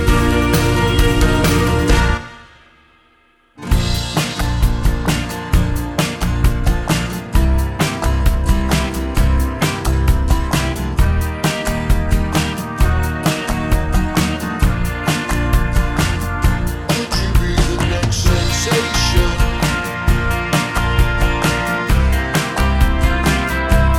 Two Semitones Down Jazz / Swing 4:09 Buy £1.50